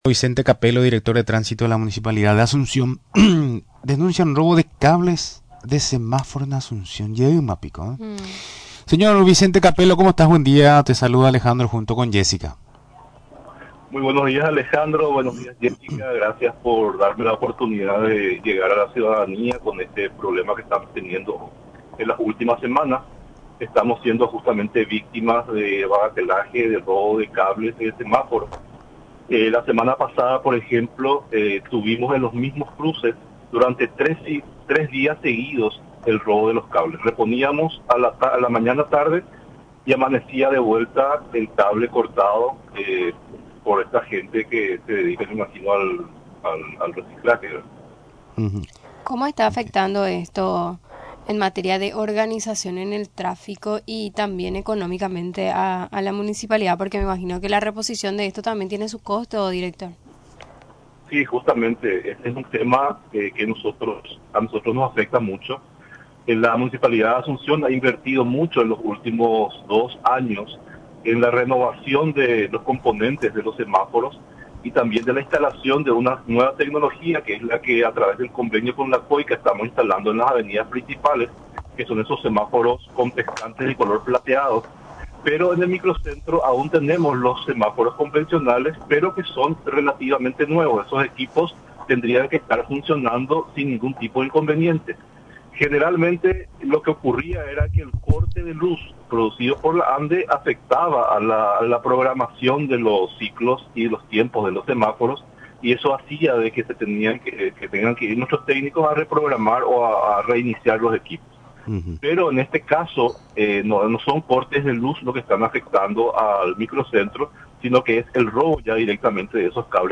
Mencionó, durante la entrevista en Radio Nacional del Paraguay, que ésta acción de los inadaptados, trae un enorme perjuicio a la institución, en cuanto al funcionamiento de los citados aparatos.